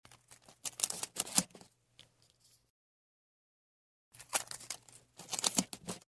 Звуки скотча
Наклеивание клейкой ленты скотч на поверхность предмета